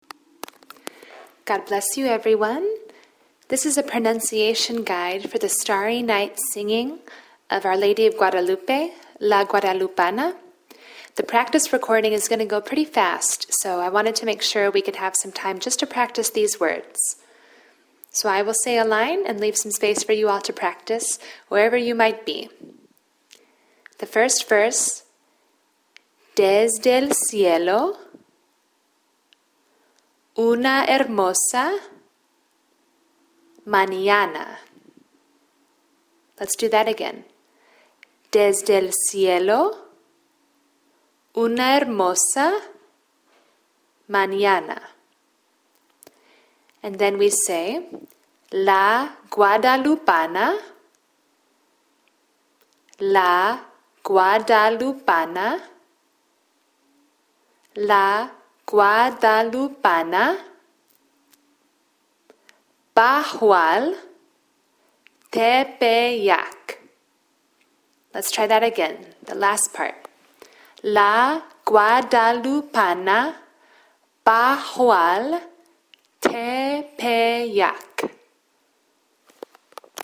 Pronunciation Guide
our_lady_of_guadalupe_pronunciation_guide.mp3